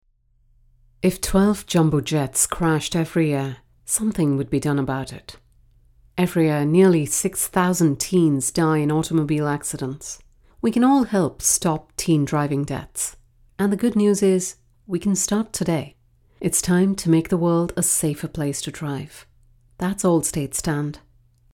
Female
Assured, Authoritative, Confident, Corporate, Friendly, Gravitas, Natural, Posh, Smooth, Warm, Versatile
Sophisticated yet approachable, intelligent, trustworthy and conversational - a voice that's easy to to listen to and has global appeal. You'll love the indistinguishable English accent tossed with British tones which will fit into the global scenario with ease.
Commercial.mp3
Microphone: Neumann TLM103, Sennheiser MKH 416